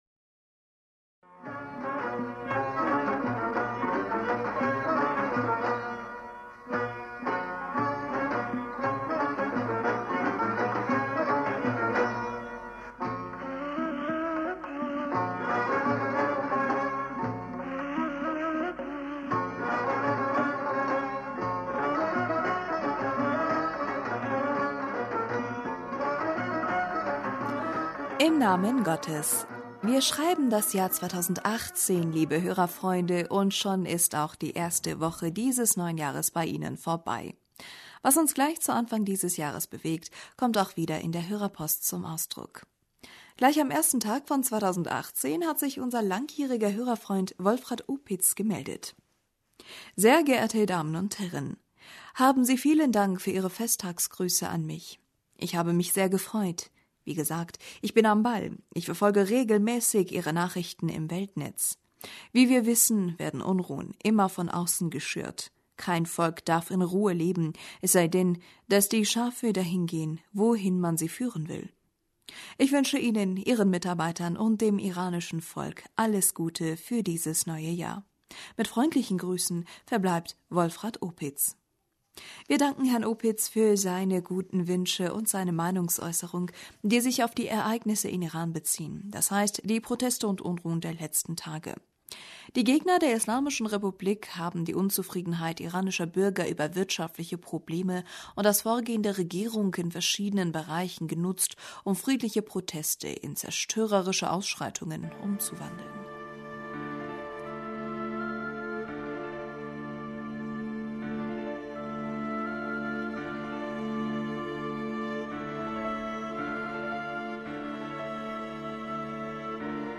Hörerpostsendung am 07. Januar 2018 - Bismillaher rahmaner rahim - Wir schreiben das Jahr 2018 liebe Hörerfreunde und schon ist auch die erste Woche...